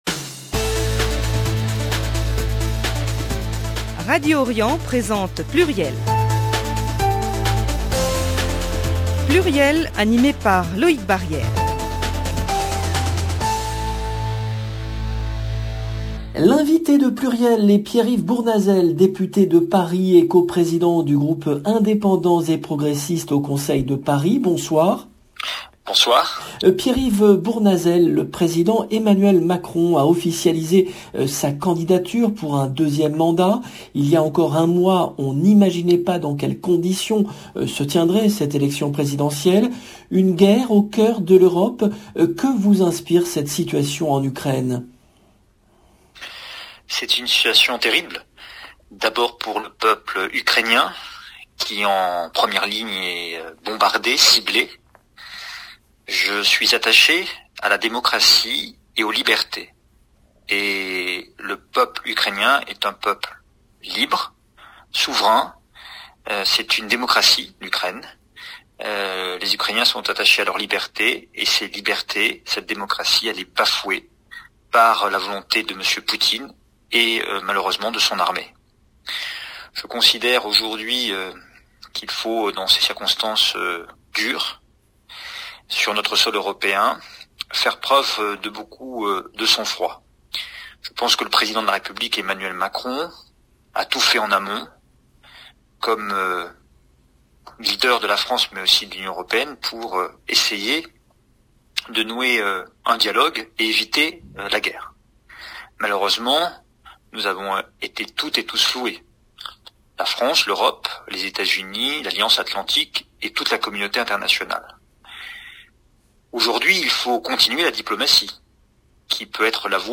Le député de Paris Pierre-Yves Bournazel,